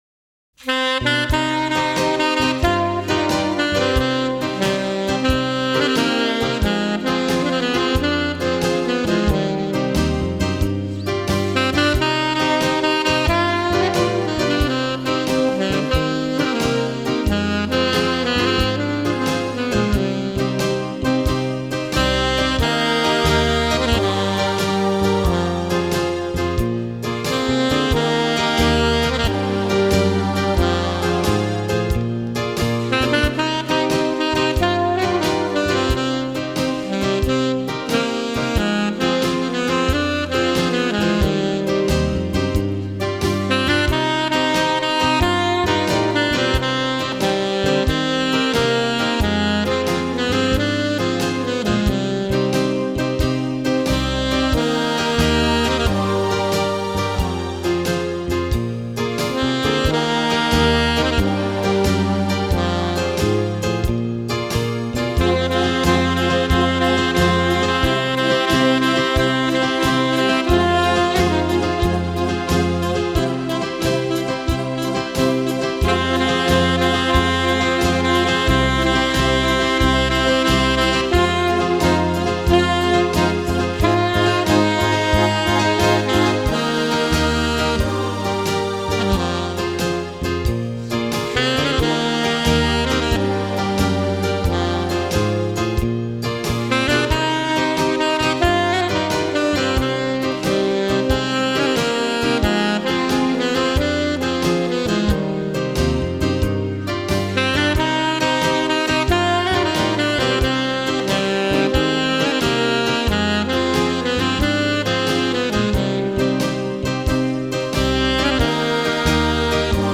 Genre: Instrumental Pop.